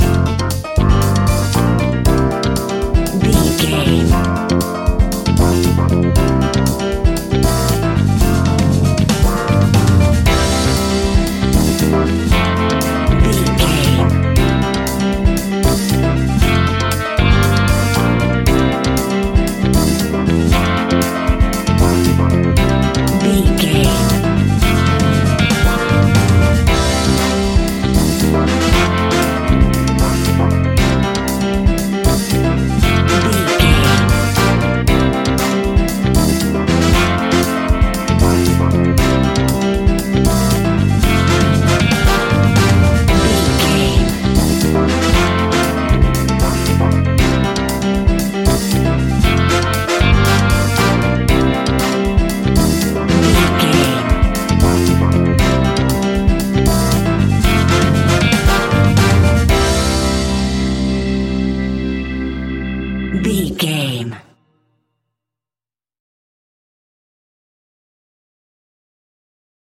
Ionian/Major
flamenco
instrumentals
maracas
percussion spanish guitar